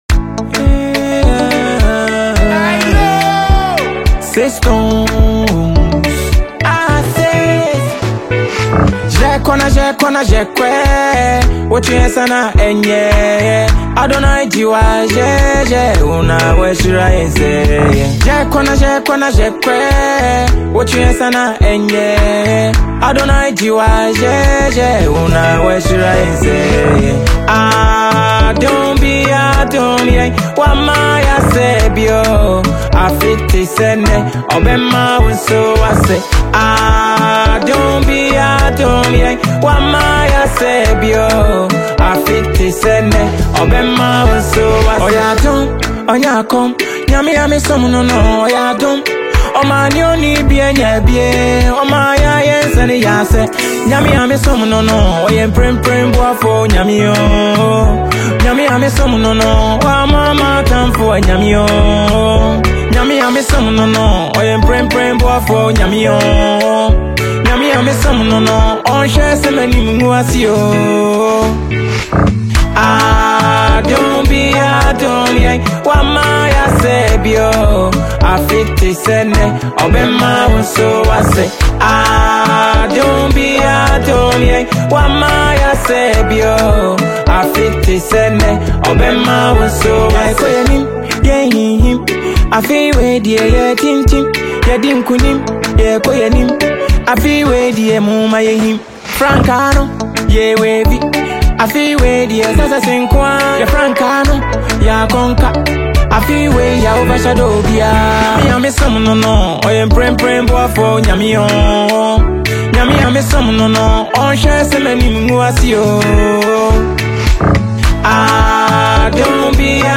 His vocals are smooth and emotive
with an infectious beat that uplifts and inspires.